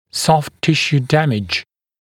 [sɔft ‘tɪʃuː ‘dæmɪʤ] [-sjuː][софт ‘тишу: ‘дэмидж] [-сйу:]повреждение мягких тканей